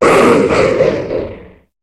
Cri de Draïeul dans Pokémon HOME.